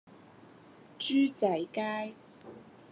Голоса - Гонконгский 14